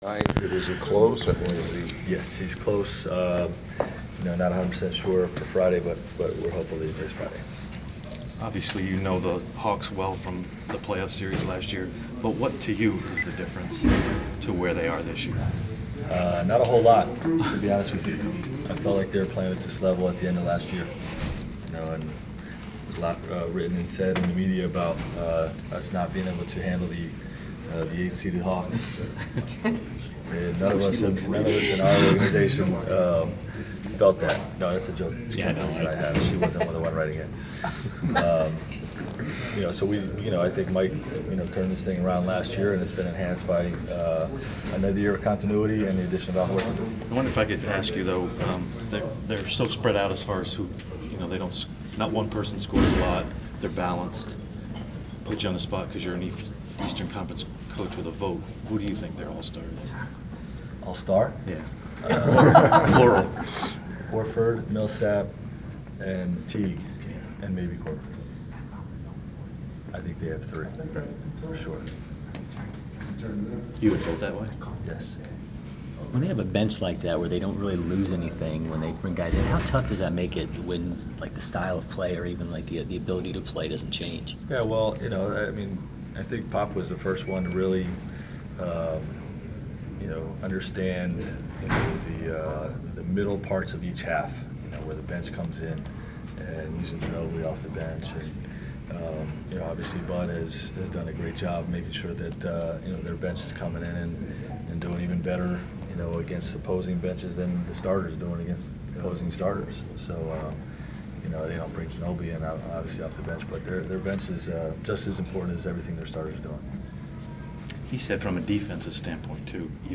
Inside the Inquirer: Pregame presser with Indiana Pacers’ Frank Vogel (1/21/15)
We attended the pregame presser of Indiana Pacers’ head coach Frank Vogel before his team’s road contest against the Atlanta Hawks on Jan. 21. Topics included the overall health of the Pacers, which Hawks he believes should be All-Stars and the improvements of the Hawks since last season’s playoff series.